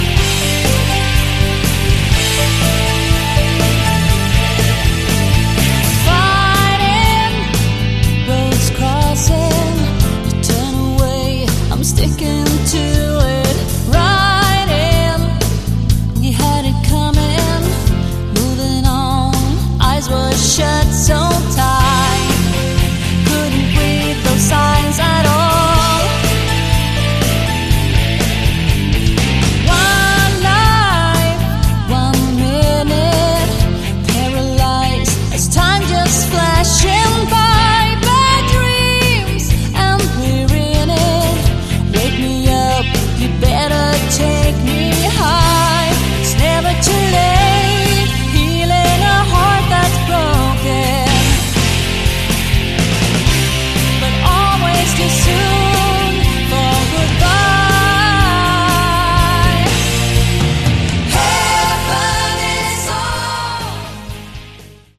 Category: AOR
guitar, keyboards, backing vocals
bass, drums